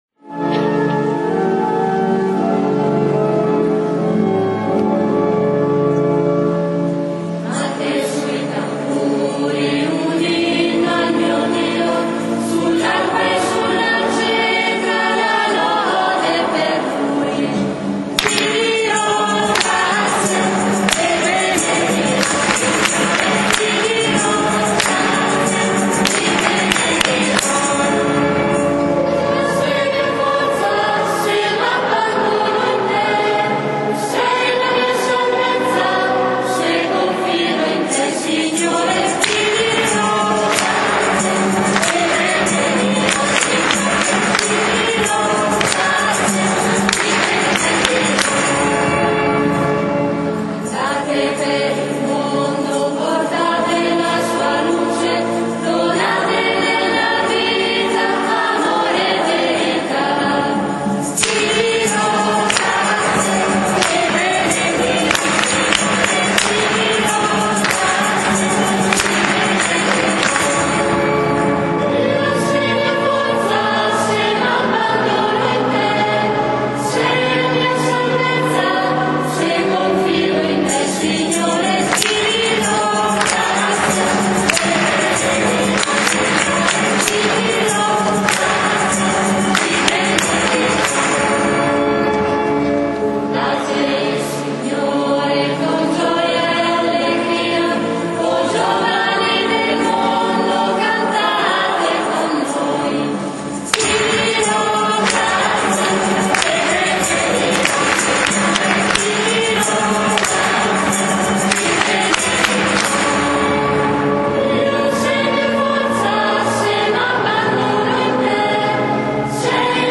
canto: Ritmate sui tamburi